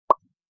bubble_pop.wav